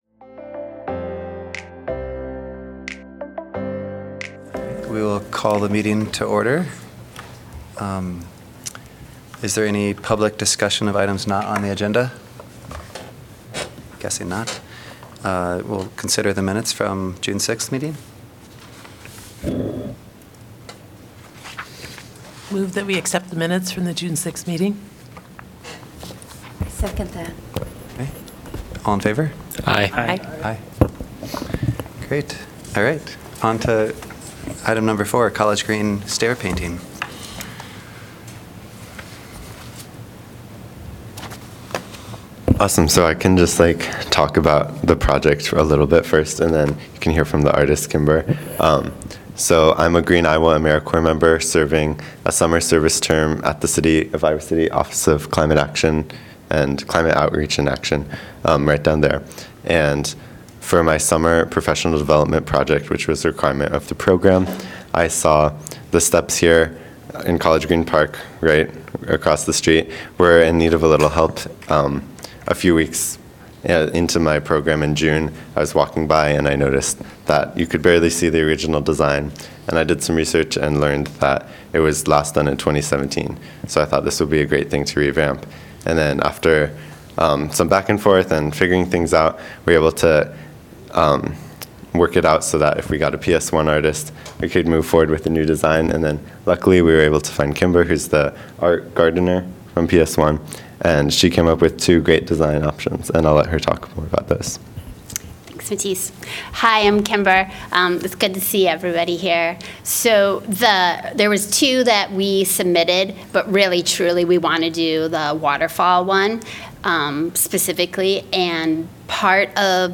The regular monthly meeting of the Public Art Advisory Committee.